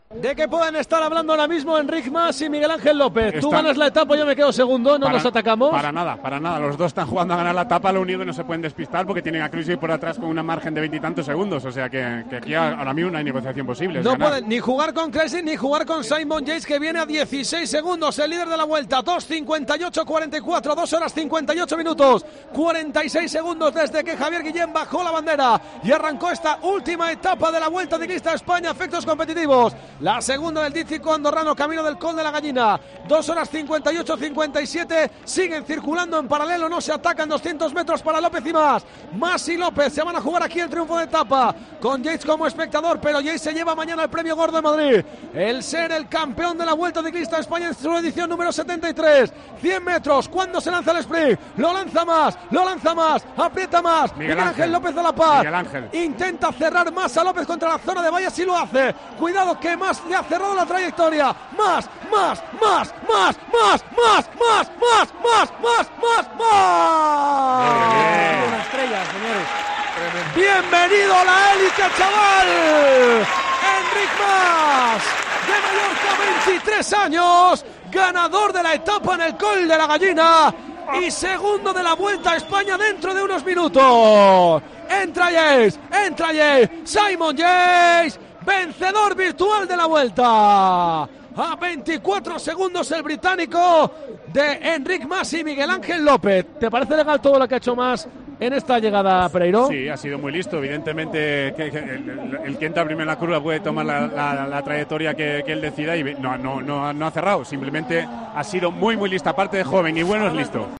Así narramos en Tiempo de Juego la victoria de Enric Mas en el Coll de La Gallina